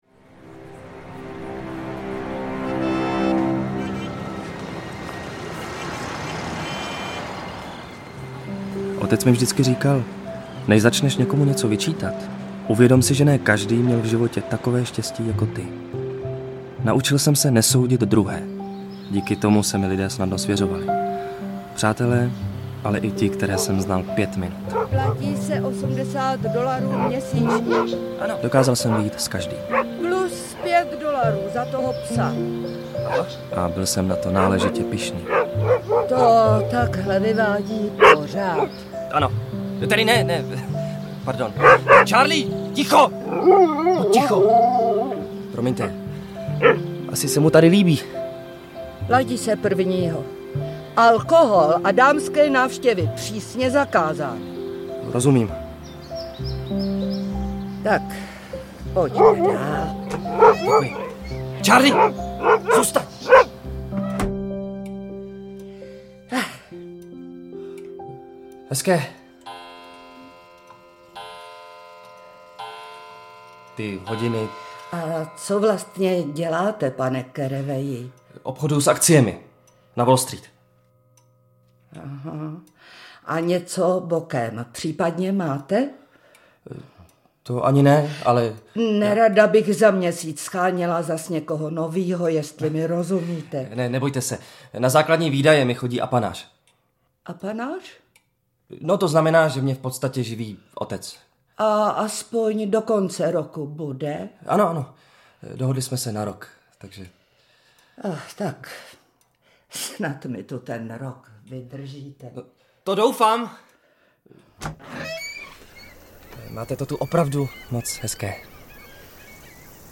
Velký Gatsby audiokniha
Audio kniha
Ukázka z knihy